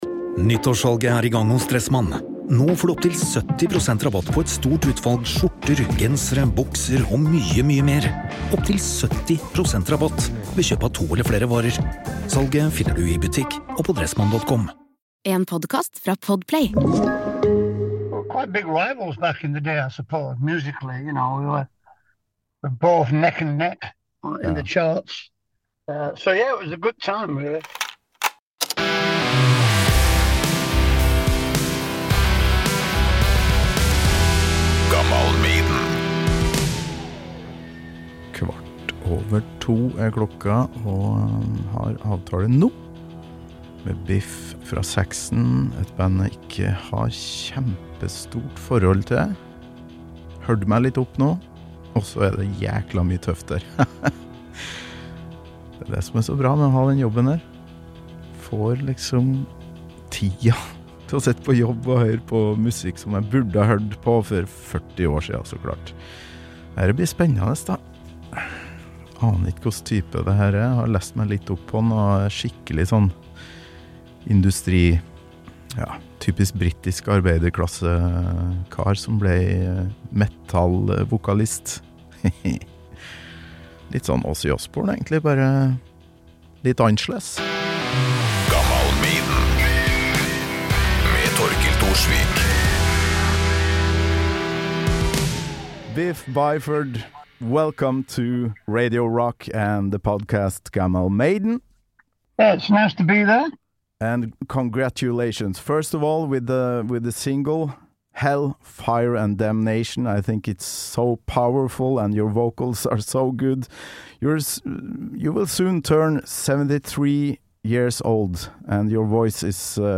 Nok en gang et intervju gjort på kort varsel, men for en herlig fyr. 73 år gamle Biff Byford og Saxon var Iron Maidens rivaler og venner på 80-tallet.